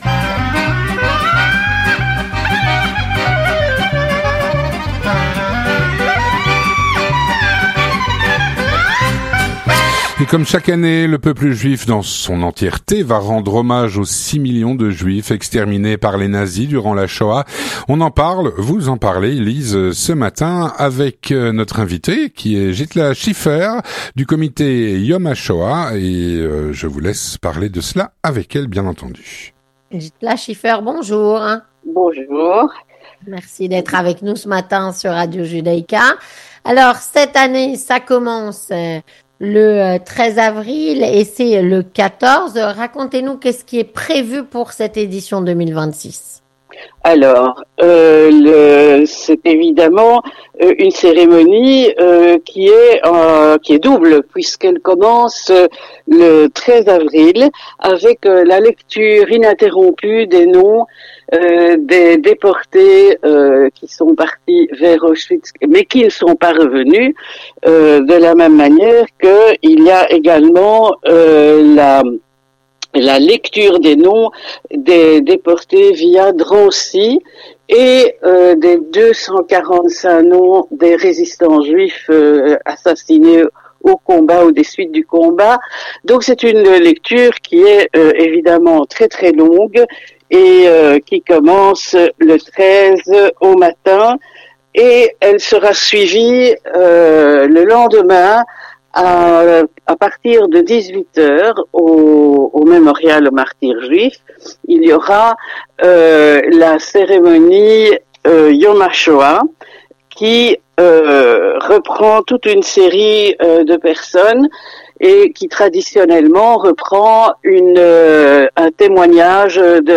3. L'interview communautaire